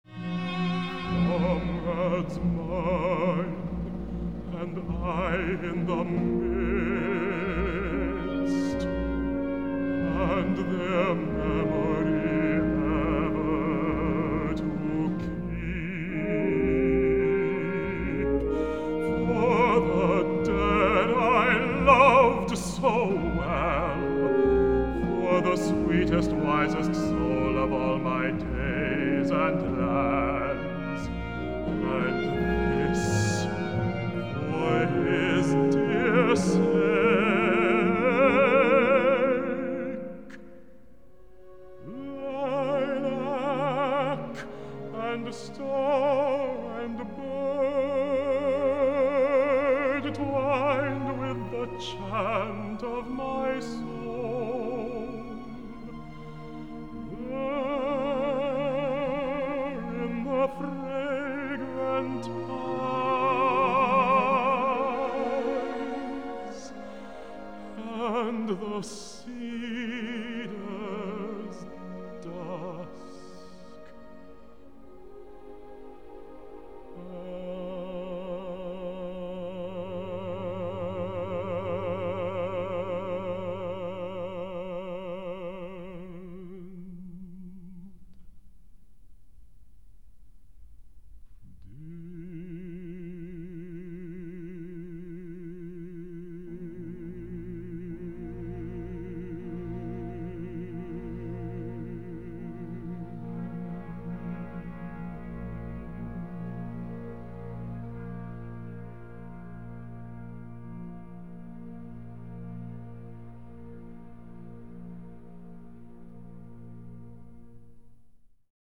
Instrumentation: baritone, orchestra